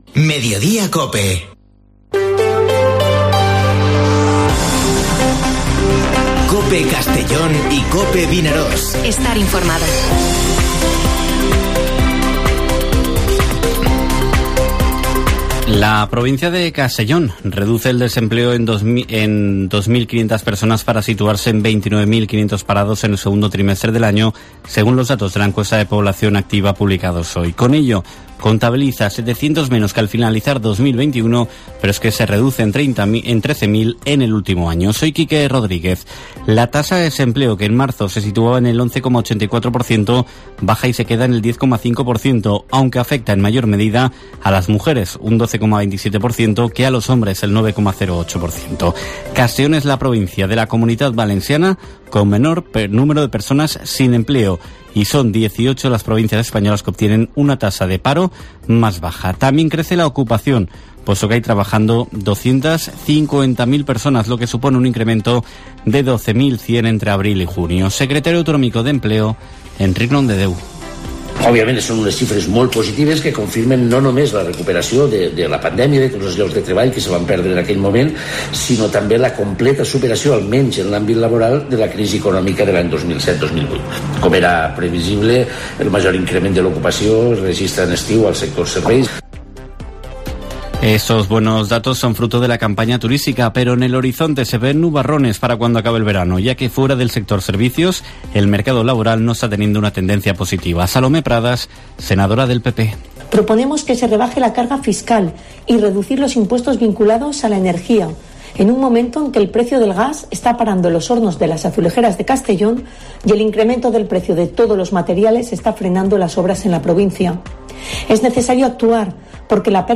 Informativo Mediodía COPE en la provincia de Castellón (28/07/2022)